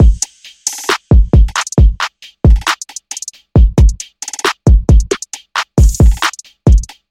陷阱鼓乐
Tag: 140 bpm Trap Loops Drum Loops 2.31 MB wav Key : Unknown